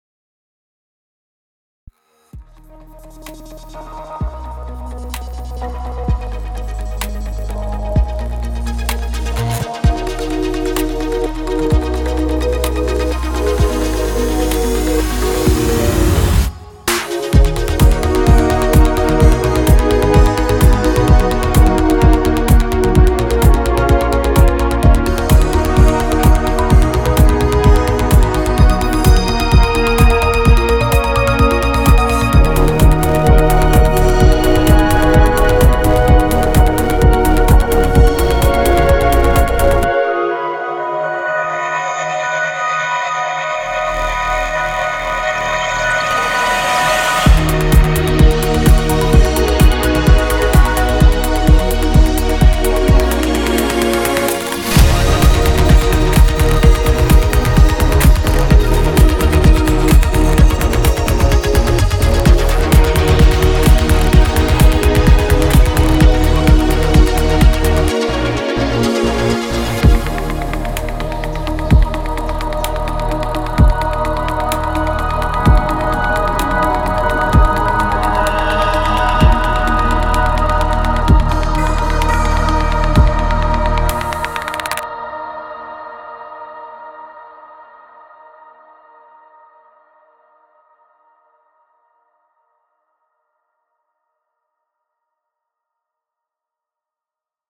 100 immersive presets of cinematic and sci fi pads and atmospheres, Sequences, FXs and others.
• Cinematic Sci Fi Soundscapes: From vast, shimmering cosmic ambiences to deep, evolving drones, each preset captures the vastness and enigma of interstellar space.
• High Quality, Ready to Use: Crafted with precision to ensure rich harmonic content, wide spatial depth, and expressive modulation — plug and play in your next project.
• * The video and audio demos contain presets played from Quantum Sphere sound bank, every single sound is created from scratch with Spire.
• * All sounds of video and audio demos are from Quantum Sphere (except drums, bass and additional arrangements).